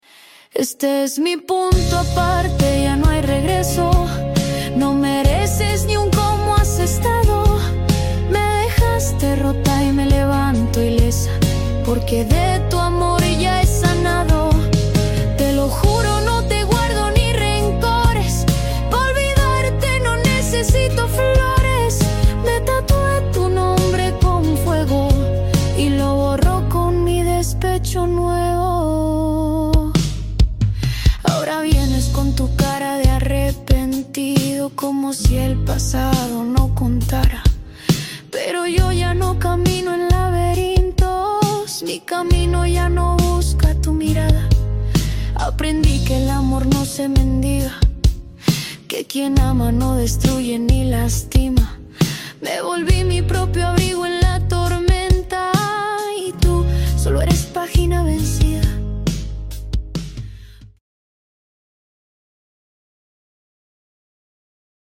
Musica Romantica Argentina Mexicana